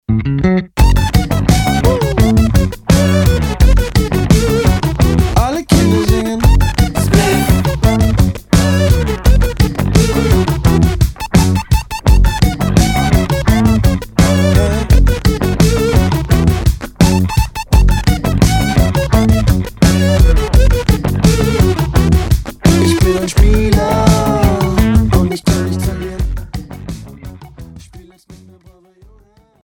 • Качество: 320, Stereo
Electronic
indie pop
Жанр: Indie Pop, Electronic